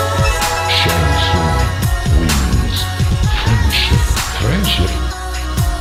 Download Mortal Kombat Friends Sound effect Button free on sound buttons.